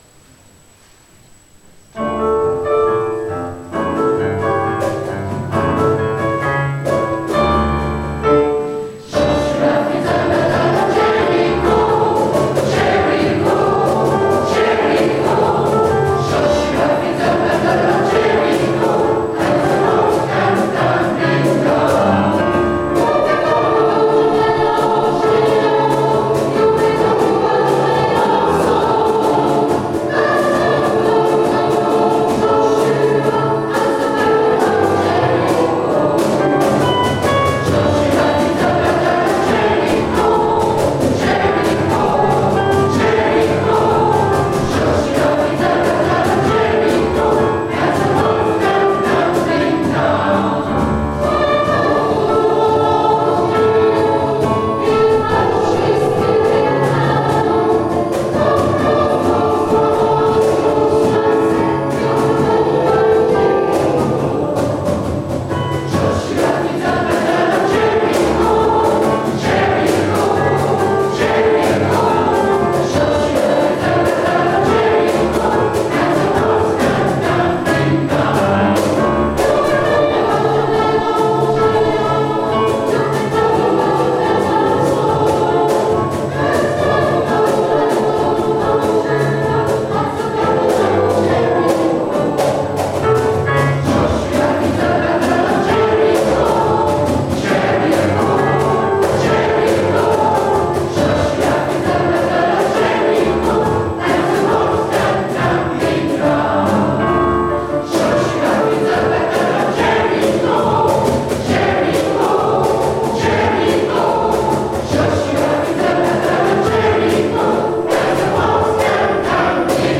Pour ceux qui vous sont proposés à l'écoute, vous voudrez bien excuser les parasites et la qualité inégale des enregistrements, tous réalisés en public.
Joshua fit the battle of Jericho (2.98 Mo) Gospel américain USA XIXème